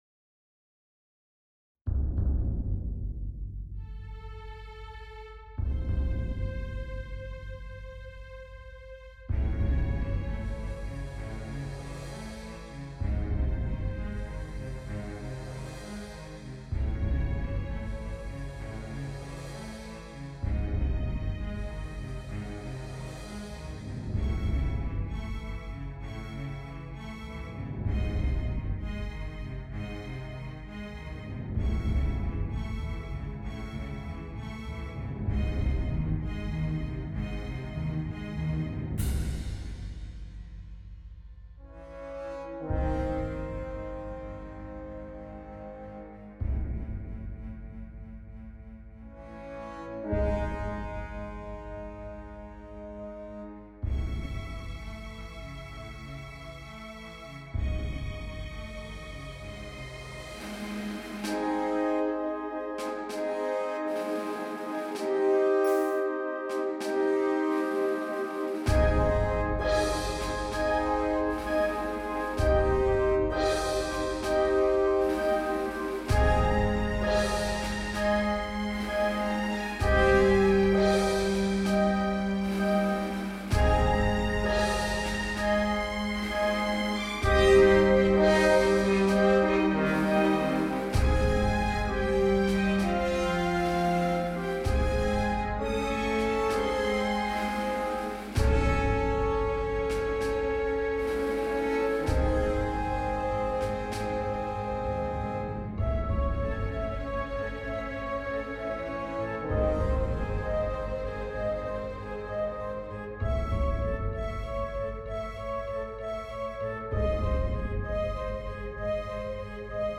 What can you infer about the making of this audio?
I doubled the length, added a new section, and then transitioned back to the beginning.Â I updated the mastering (new reverb convolution, new Voxengo 7-band EQ):